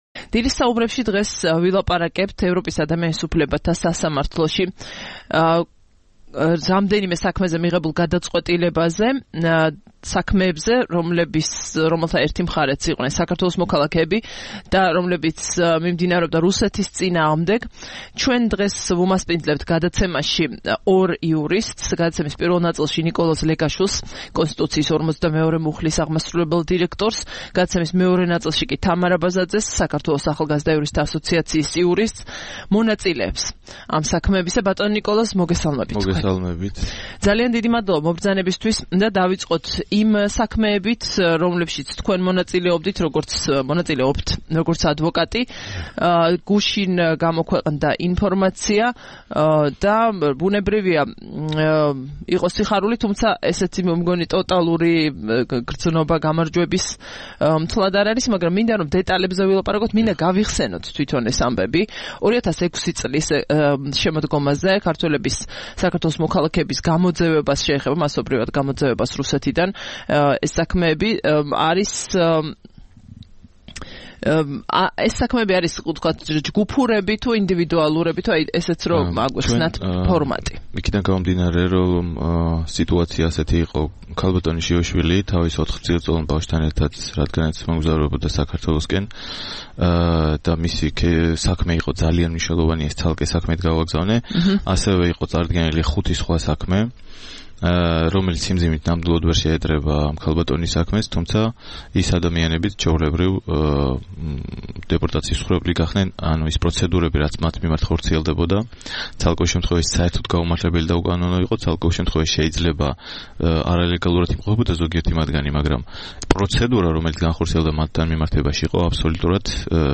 21 დეკემბერს რადიო თავისუფლების "დილის საუბრების" სტუმრები იყვნენ იურისტები